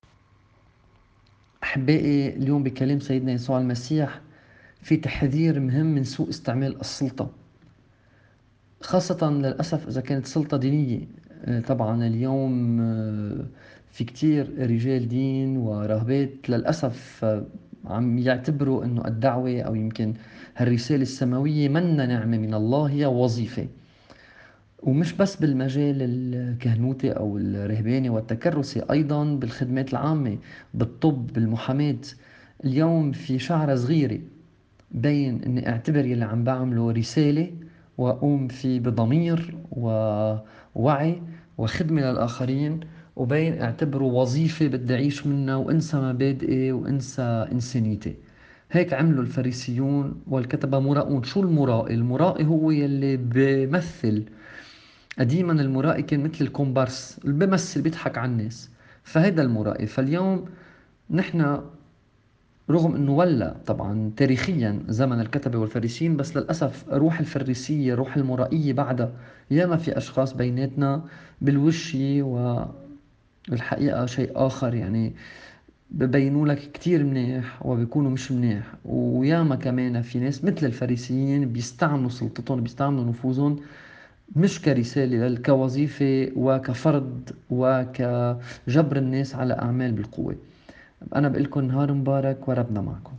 تأمّل في إنجيل يوم ٢٦ تمّوز ٢٠٢١MP3 • 221KB